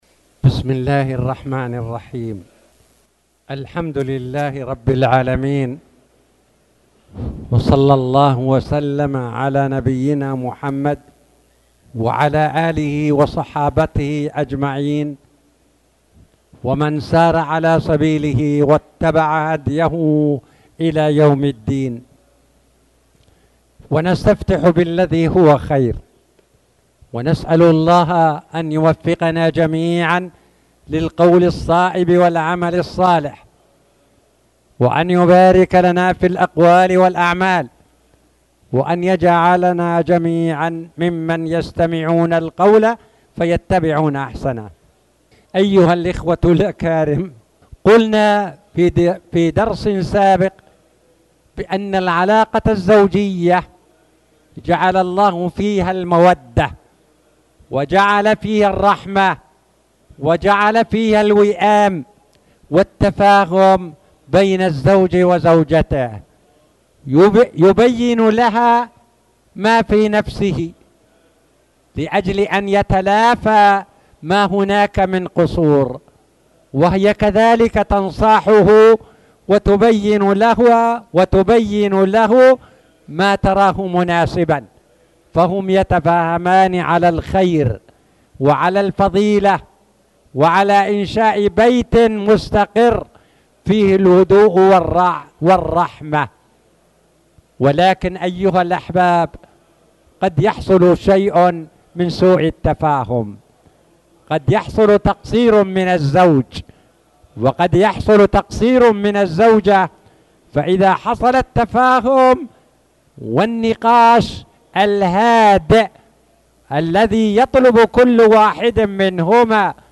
تاريخ النشر ٦ جمادى الآخرة ١٤٣٨ هـ المكان: المسجد الحرام الشيخ